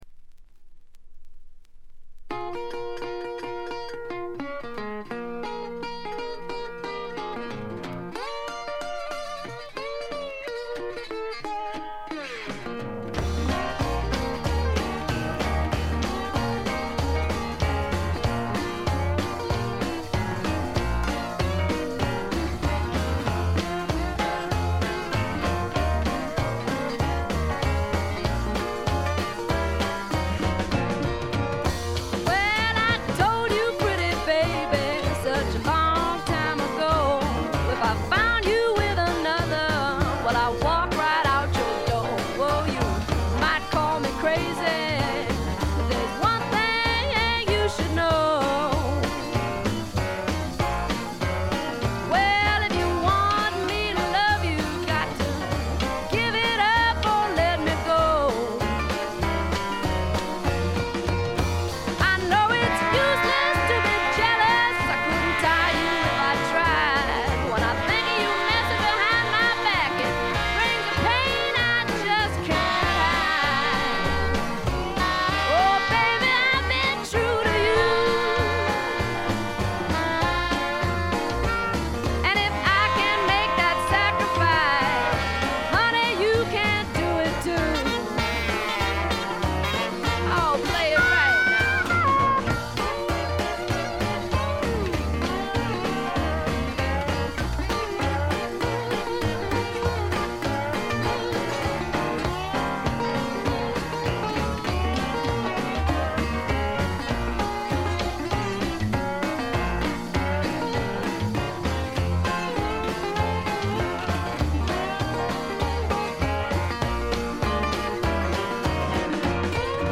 ウッドストック・べアズビル録音の名盤としても有名です。